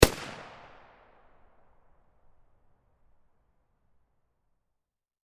Звуки стартового пистолета
Звук одиночного выстрела стартового пистолета на стадионе в воздух